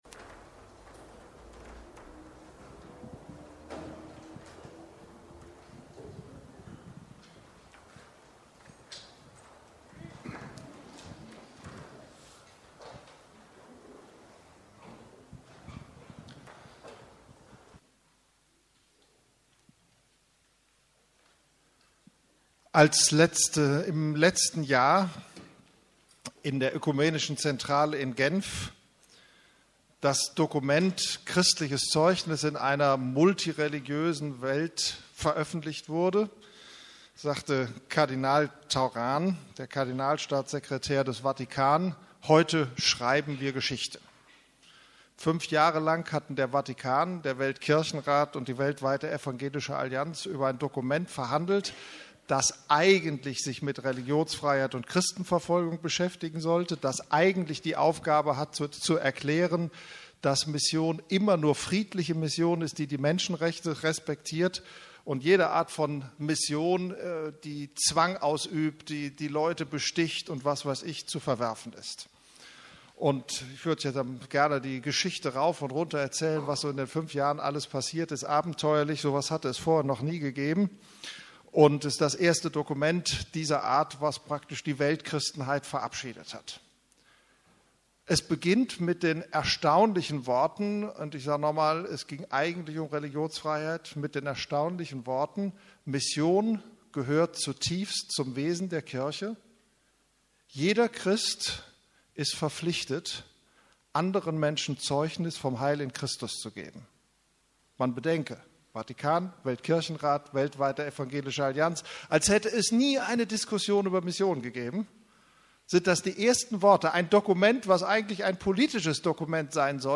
Gott, der Missionar ~ Predigten der LUKAS GEMEINDE Podcast